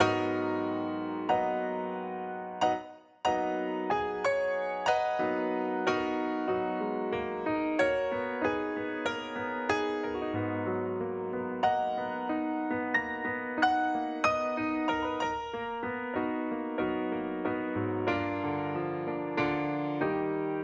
08 piano C.wav